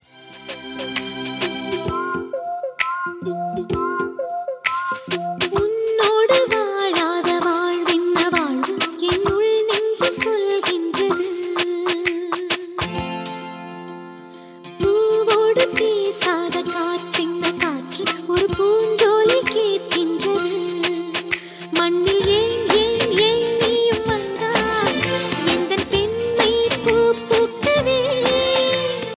best flute ringtone download